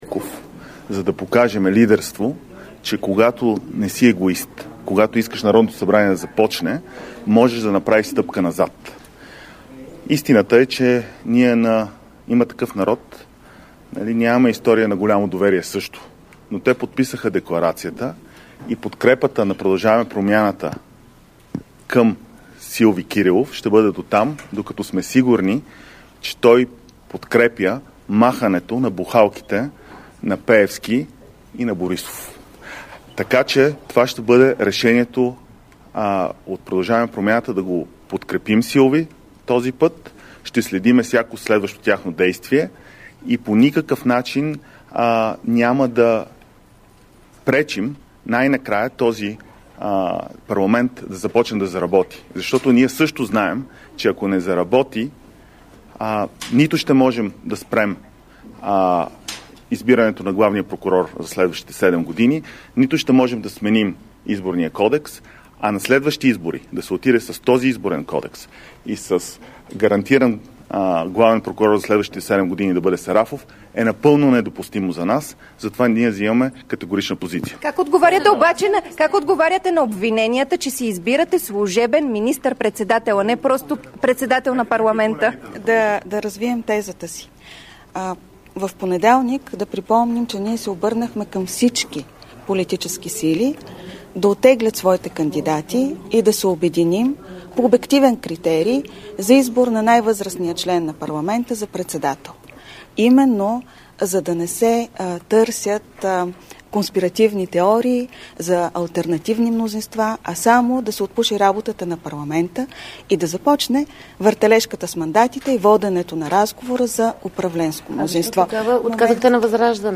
10.40 - Брифинг на Кирил Петков и Надежда Йорданова от ПП-ДБ. - директно от мястото на събитието (Народното събрание )